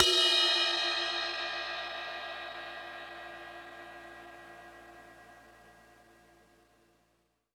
Ride_9.wav